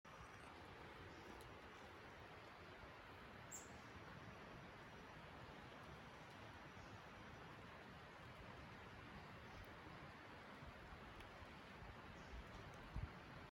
It sounds like we are near a highway. We aren’t.
LaFortunaForestSounts04.mp3